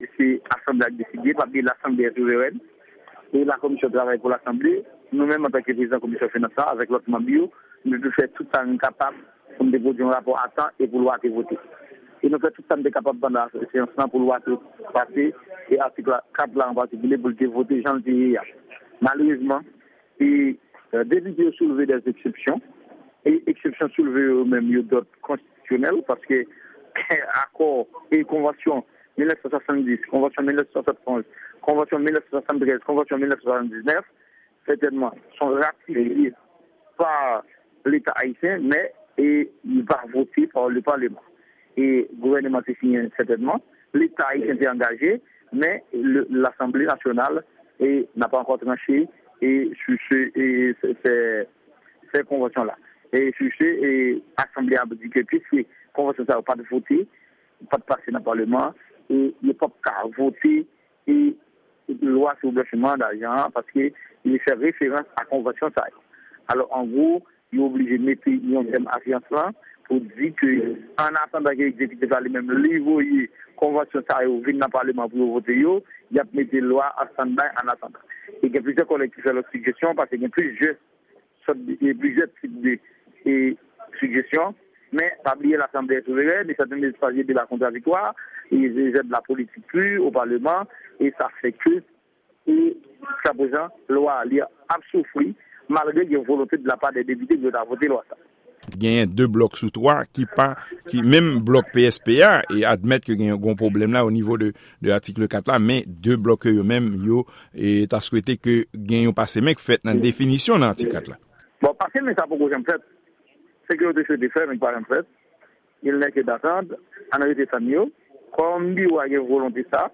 Deklarasyon depite A. Rodon Bien-Aimé, prezidan komisyon finans chanm bas la nan palman ayisyen an.